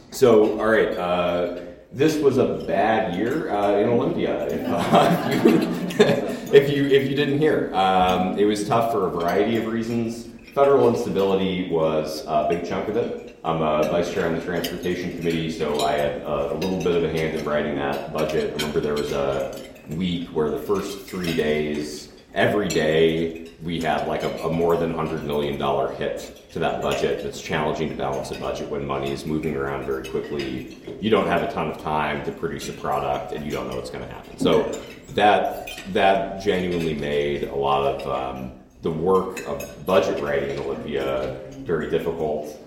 Port Angeles – State Representative Adam Bernbaum of the 24th District was the guest speaker at Wednesday’s Port Angeles Chamber luncheon, presenting his take on how this year’s legislative session went in Olympia.